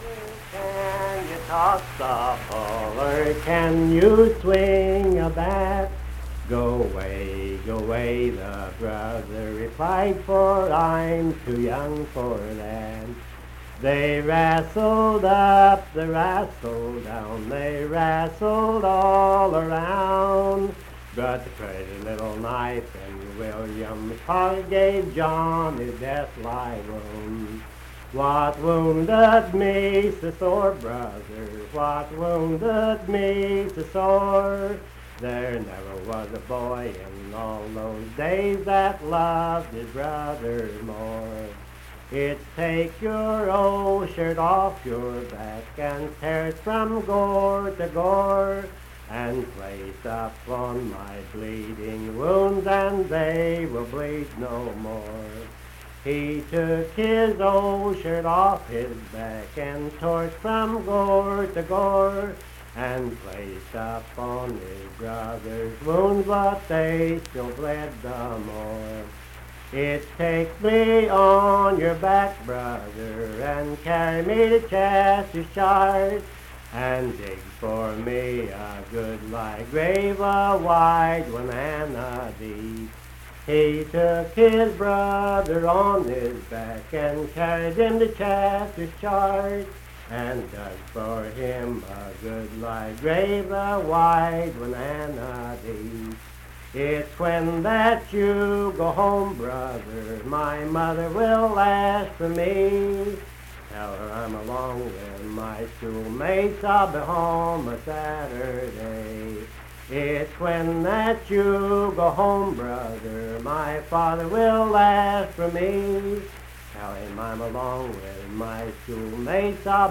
Unaccompanied vocal music
Verse-refrain 10(4).
Performed in Hundred, Wetzel County, WV.
Voice (sung)